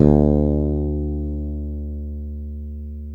Index of /90_sSampleCDs/East Collexion - Bass S3000/Partition A/FRETLESS-E